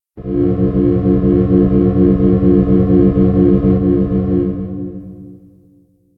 Campanas en mi cabeza